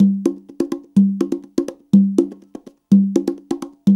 CONGA BEAT43.wav